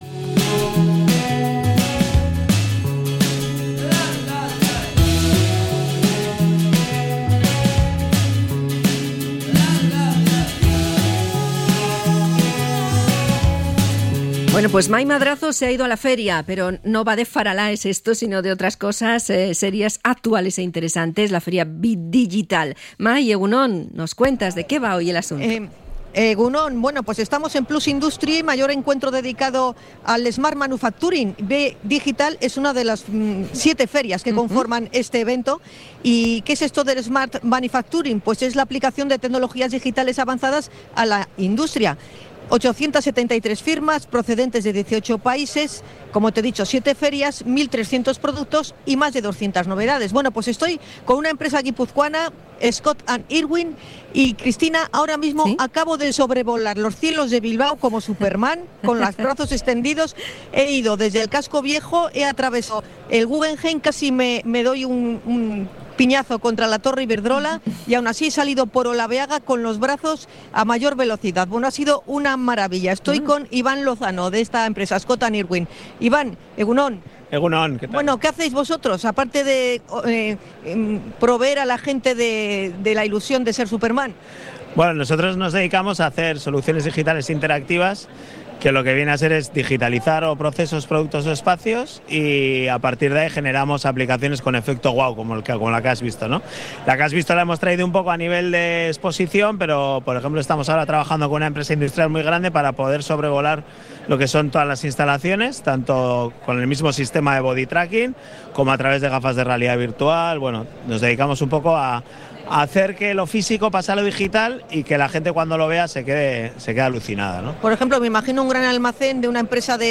Varios expositores nos muestran los productos y servicios más punteros de la industria
FERIA-BE-DIGITAL.mp3